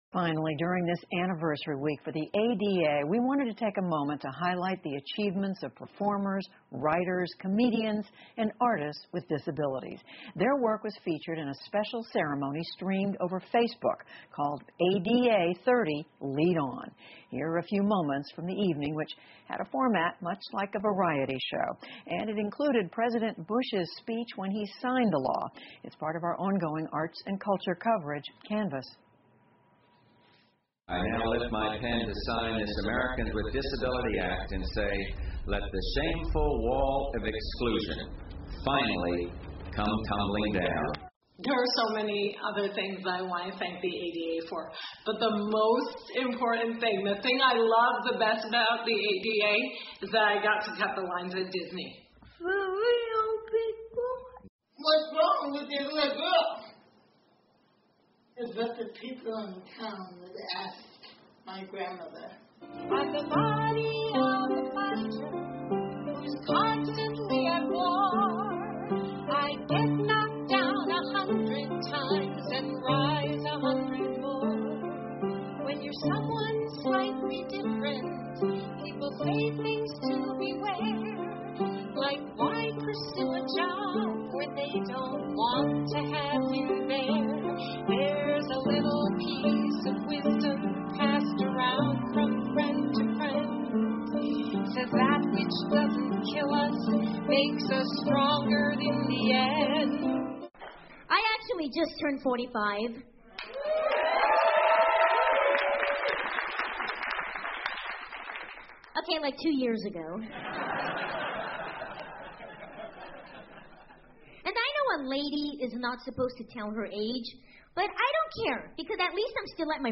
美国公共电视网PBS高端访谈节目，其所提供的高质量节目与教育服务，达到媒体告知(inform)、启发(inspire)与愉悦(delight)的社会责任。